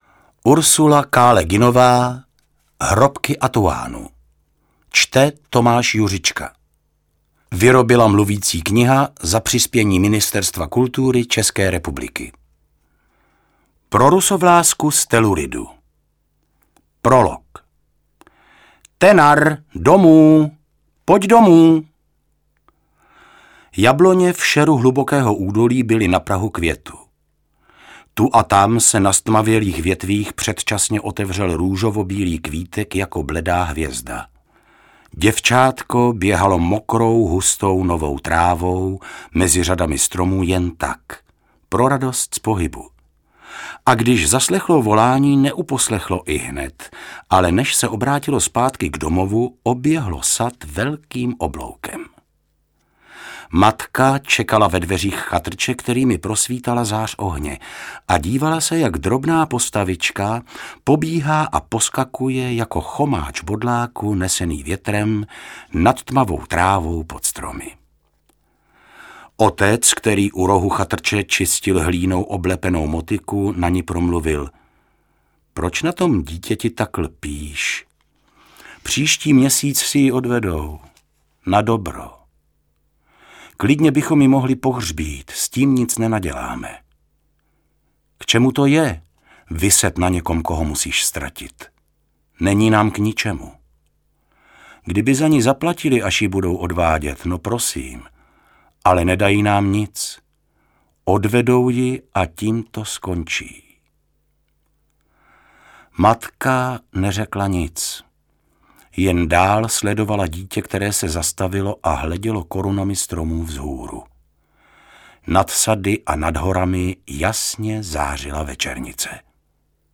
Fantasy román volně navazující na předešlý příběh ze Zeměmoří - "Čaroděj Zeměmoří" .